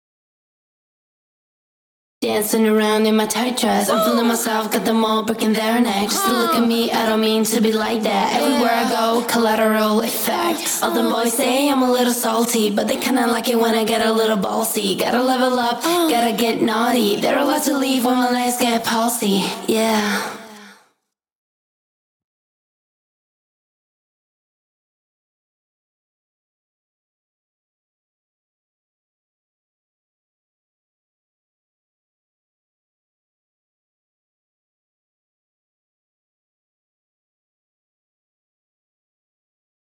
ON（ボーカル単体）
Density Moduleを使うと、ギターやボーカルの存在感がしっかり増し、曲全体に厚みが出ます